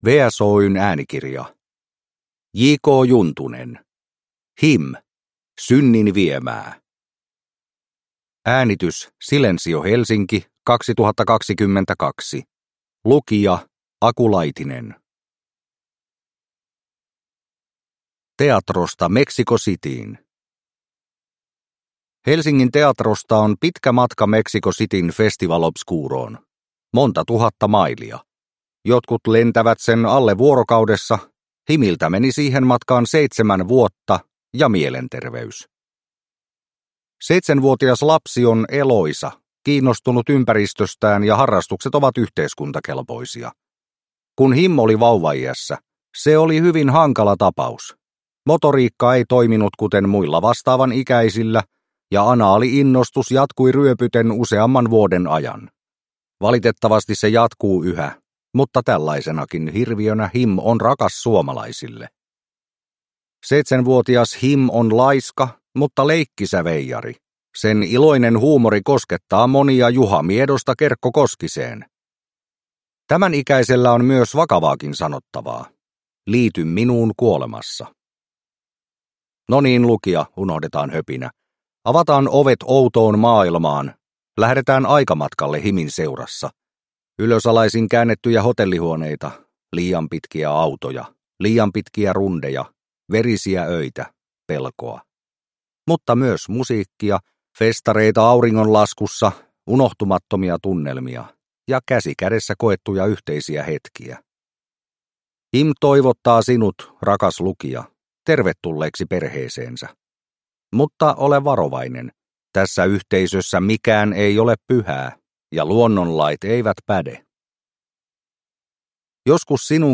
HIM - Synnin viemää – Ljudbok – Laddas ner